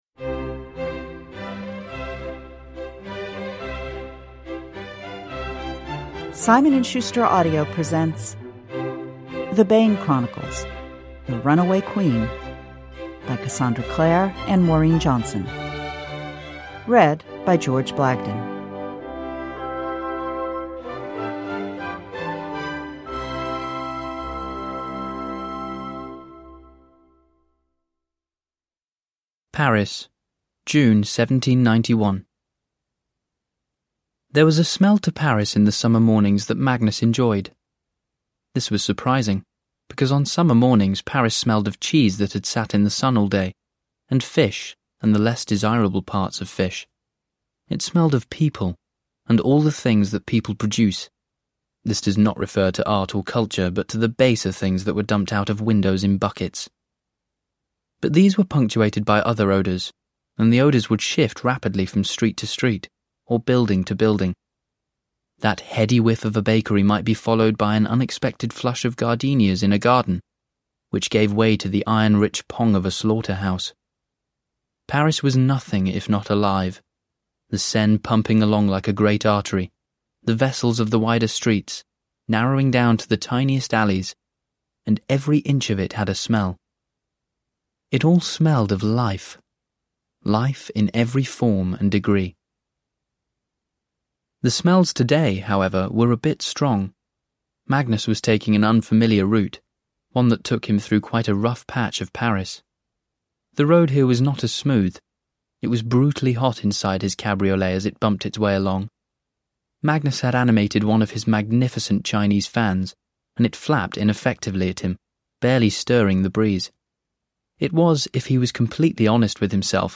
Uppläsare: George Blagden
Ljudbok